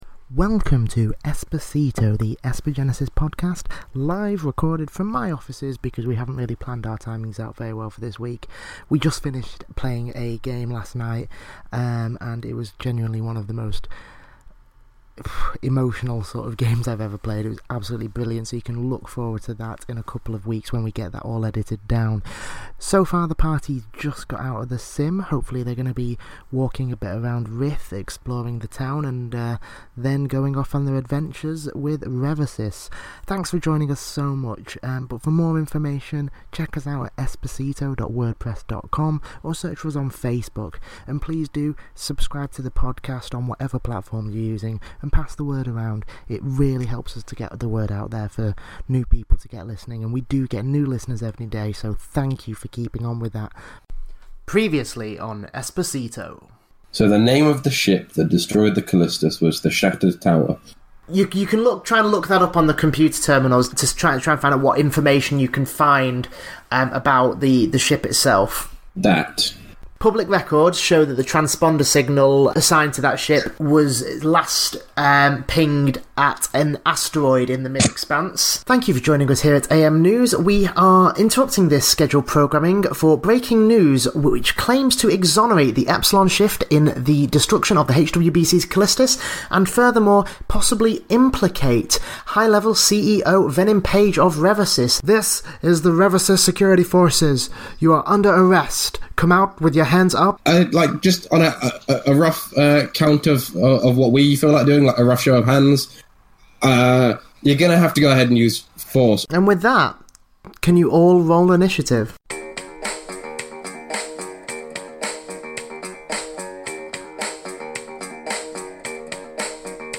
This, the only live action Esper Genesis podcast, tells the ongoing saga of The Epsilon Shift, an innocent group of idiots who have been framed for a terrorist attack.